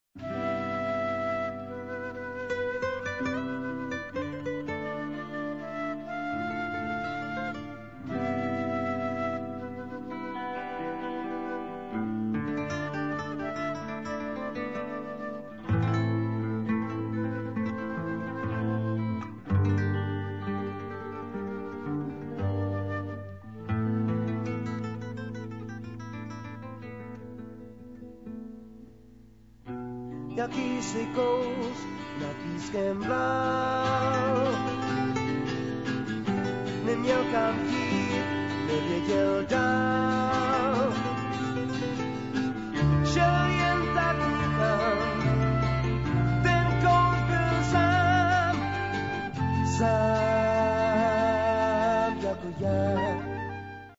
a legend of Czech rock'n'roll history.